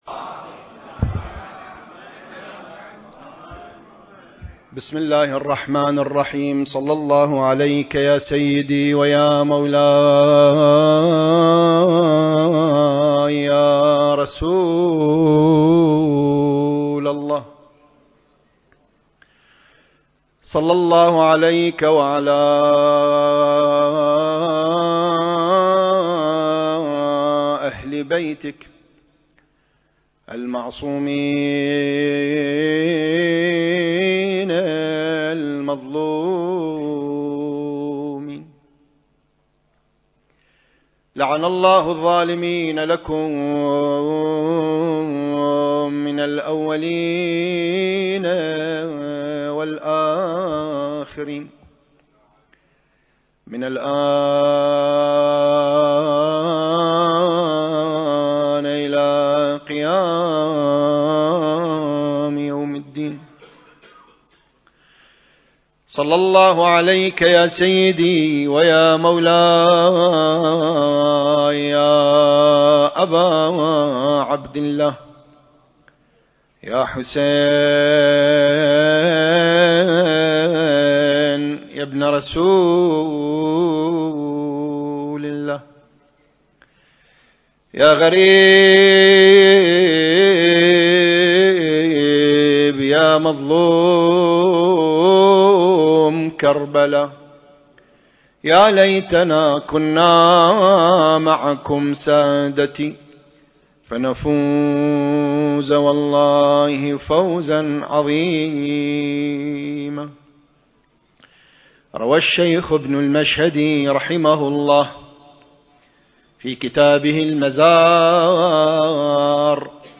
المكان: موكب النجف الأشرف/ قم المقدسة التاريخ: 1444 للهجرة